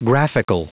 Prononciation du mot graphical en anglais (fichier audio)
Prononciation du mot : graphical
graphical.wav